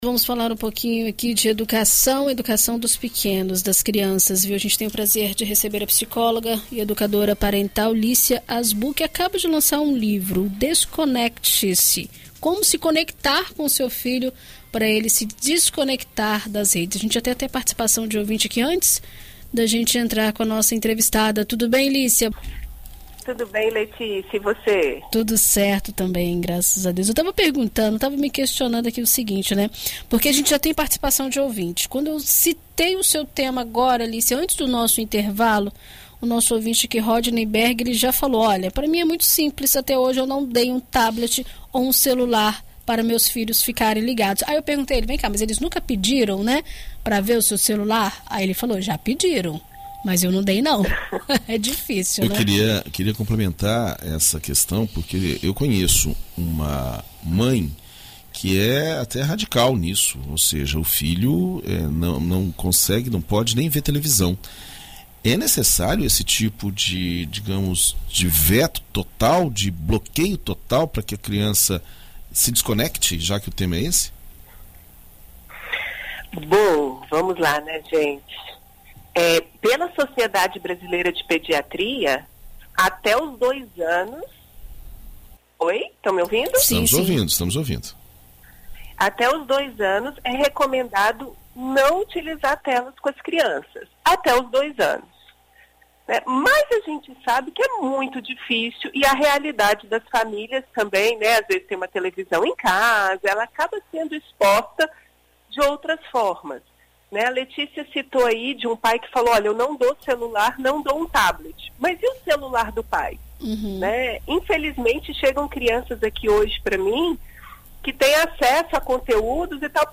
Em entrevista à BandNews FM Espírito Santo nesta quarta-feira